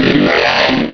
Cri de Drackhaus dans Pokémon Rubis et Saphir.